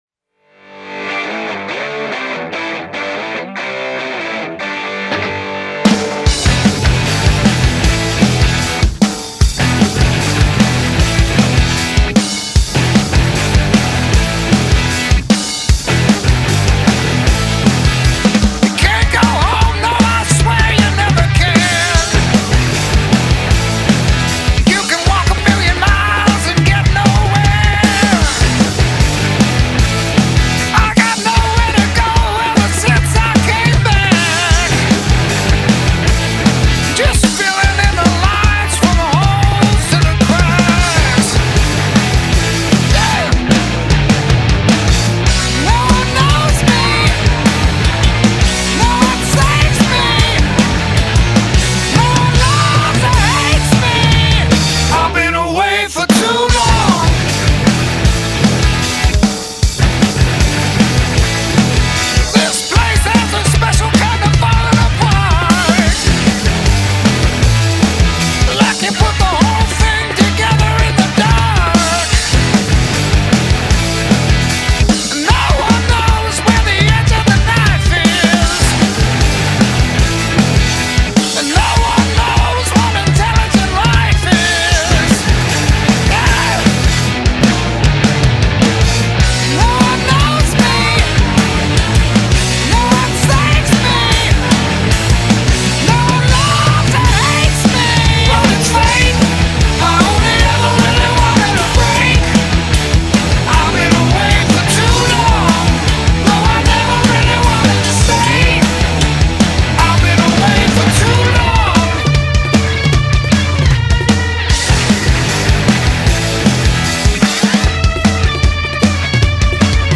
90s Seattle scene